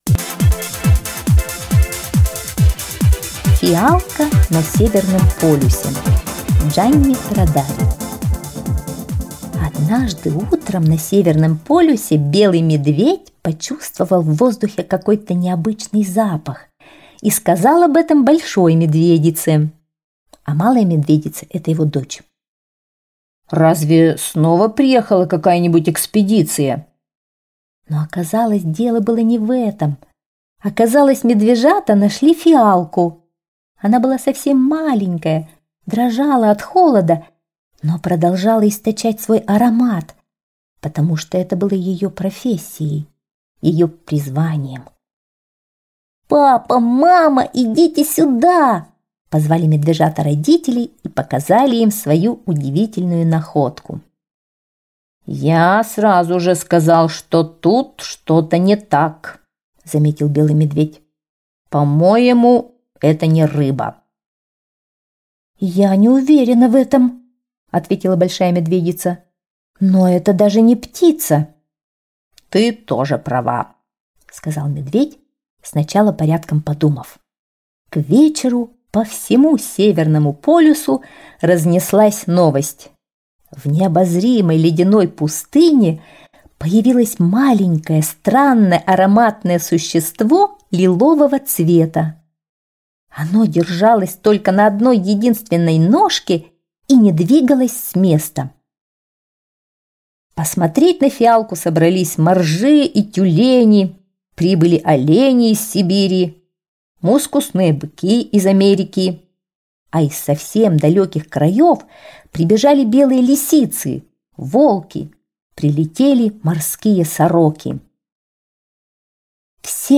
Слушать онлайн Фиалка на Северном полюсе - аудиосказка Дж. Родари.